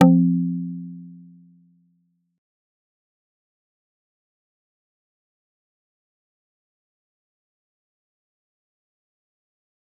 G_Kalimba-F3-mf.wav